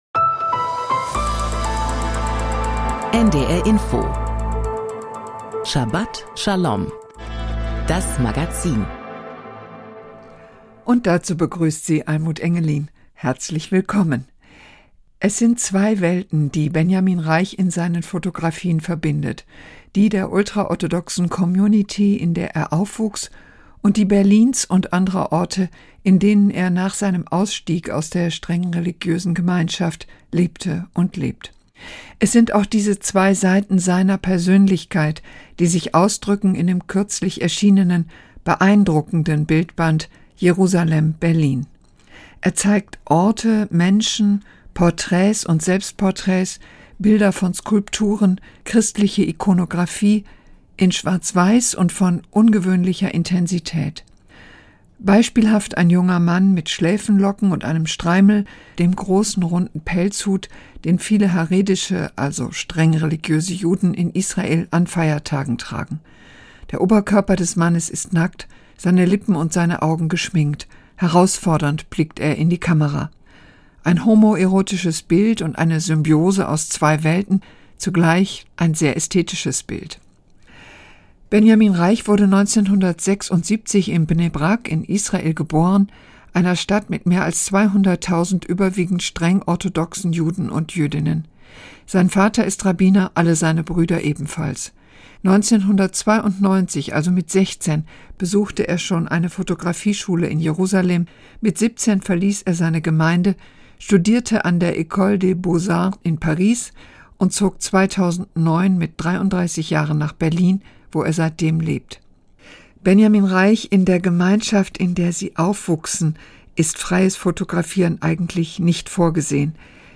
Interview
Thora-Auslegung